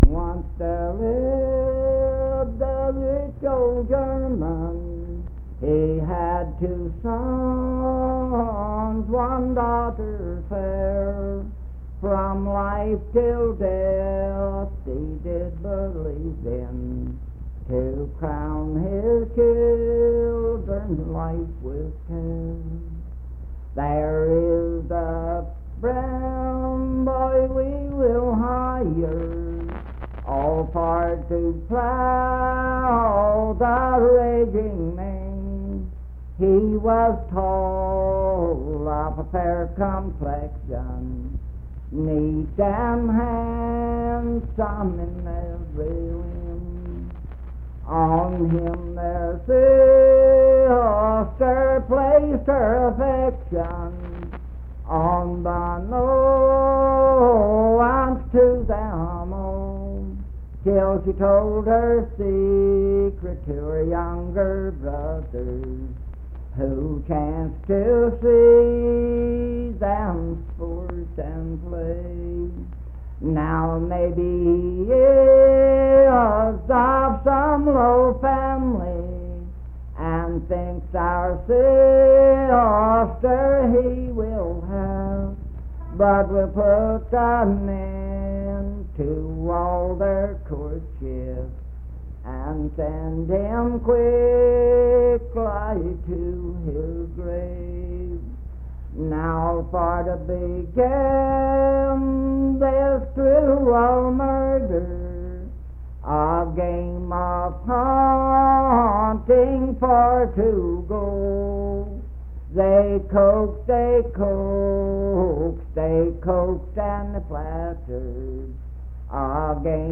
Unaccompanied vocal music performance
Voice (sung)
Spencer (W. Va.), Roane County (W. Va.)